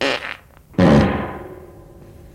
文件夹里的屁 " 屁 02
描述：从freesound上下载CC0，切片，重采样到44khZ，16位，单声道，文件中没有大块信息。准备使用！在1个文件夹中有47个屁;）
Tag: 喜剧 放屁 效果 SFX soundfx 声音